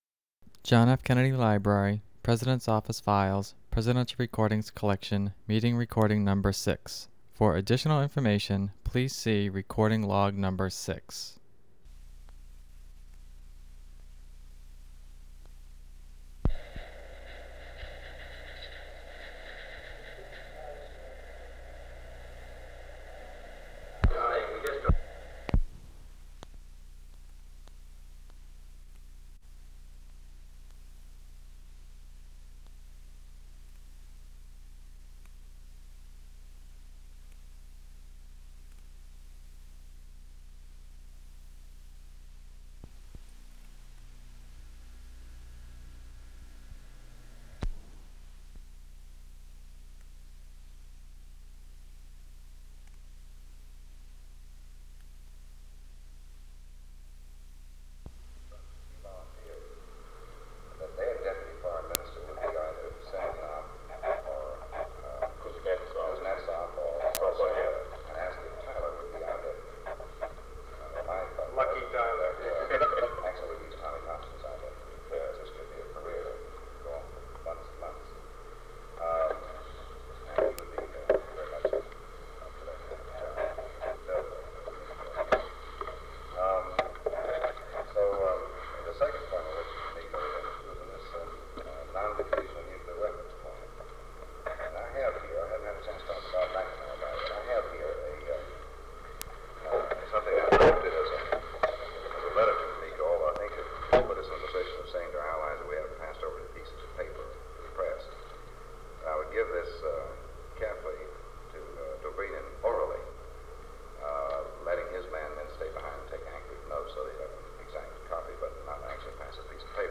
Secret White House Tapes | John F. Kennedy Presidency Meeting on Berlin Rewind 10 seconds Play/Pause Fast-forward 10 seconds 0:00 Download audio Previous Meetings: Tape 121/A57.